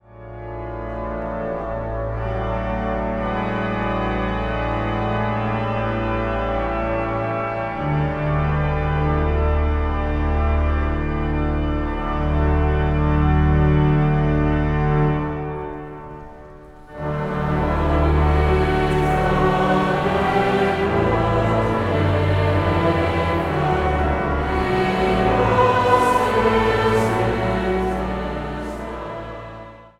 Massale samenzang & orgelspel
Zang | Samenzang